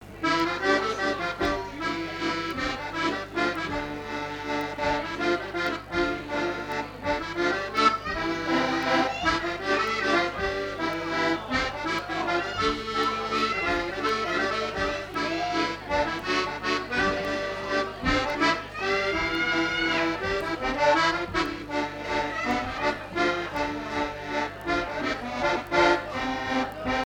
danse : java
Fête de l'accordéon
Pièce musicale inédite